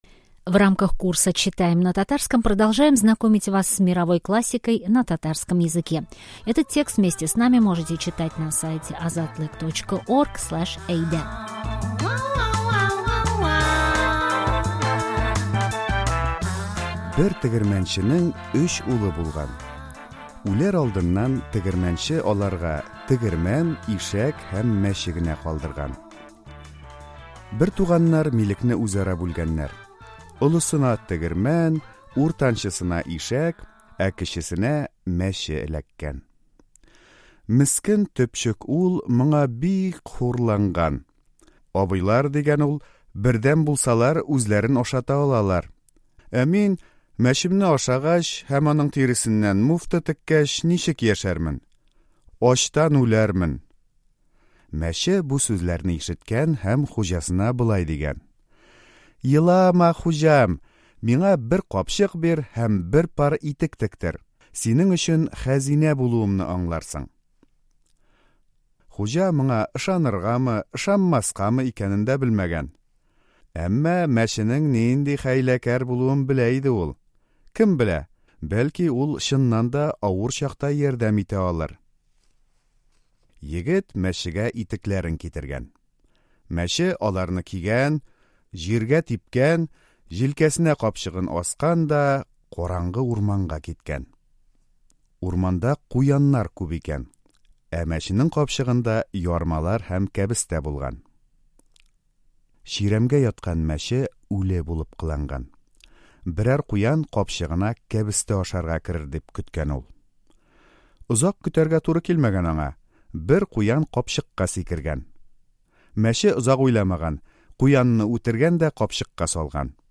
Текст мы перевели на татарский сами, максимально упростили его, записали красивую аудиосказку, добавили перевод основных фраз и тест по тексту.